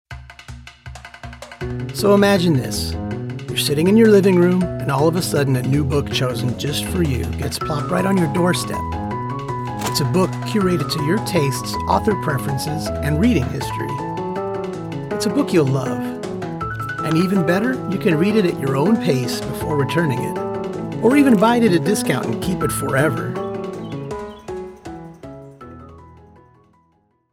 Online Ad
My voice quality is clear with a slight amount of "grit" that lends to its uniqueness.